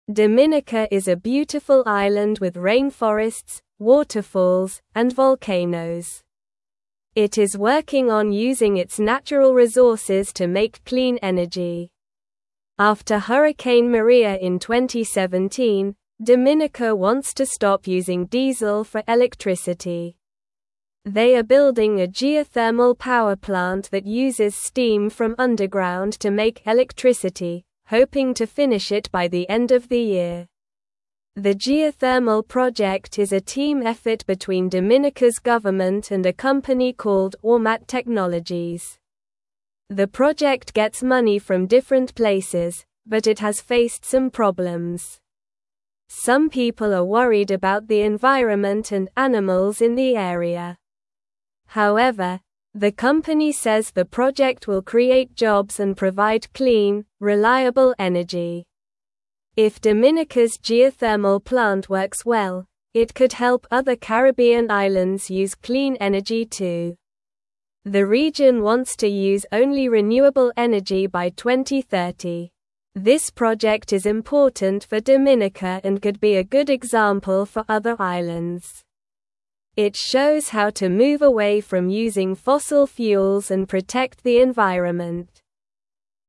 Slow
English-Newsroom-Lower-Intermediate-SLOW-Reading-Dominicas-Clean-Energy-Plan-for-a-Bright-Future.mp3